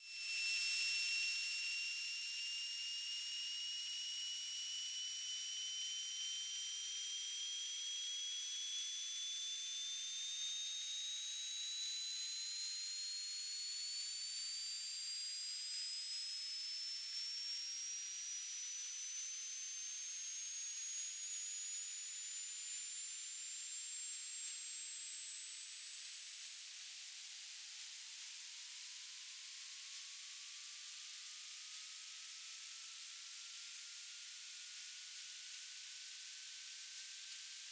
"transmitter_description": "BPSK Telemetry",
"transmitter_mode": "BPSK",